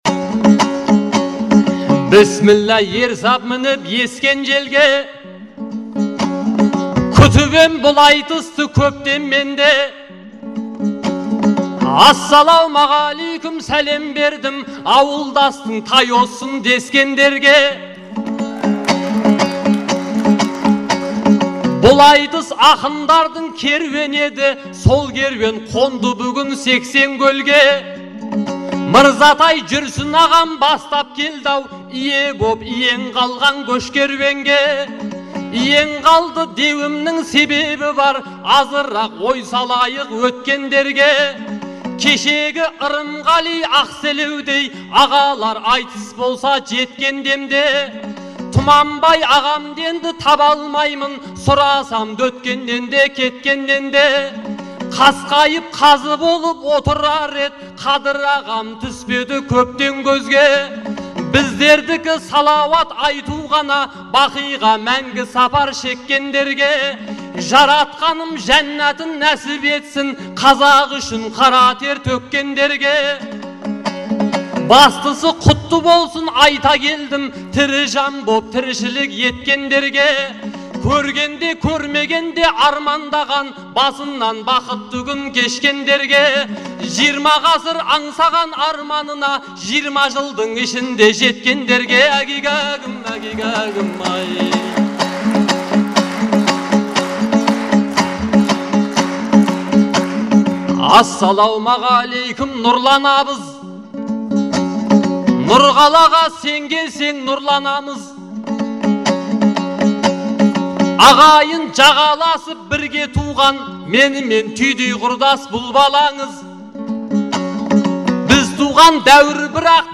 айтысы
Астана, 3 желтоқсан 2011 жыл.